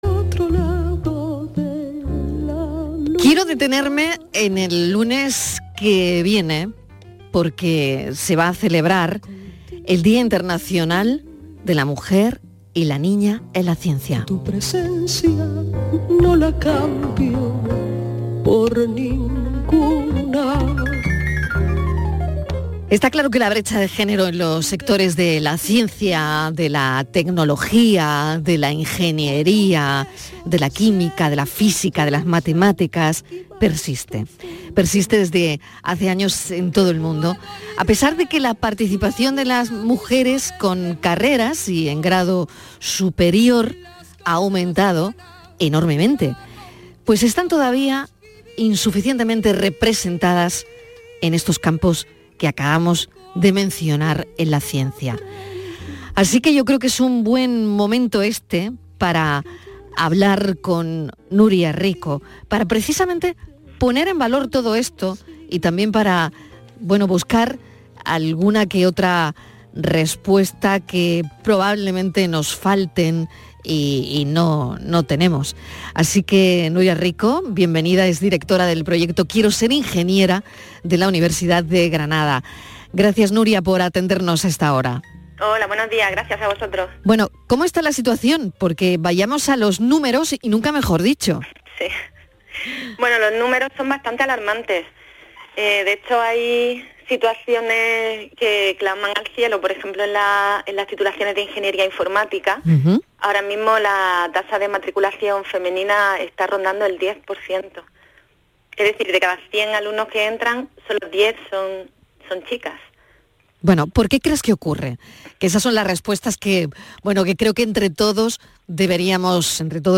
Extensa entrevista
en el magacín radiofónico de fin de semana de  Canal Sur, «La calle de enmedio» con motivo del «Día Internacional de la Mujer y la Niña en la Ciencia»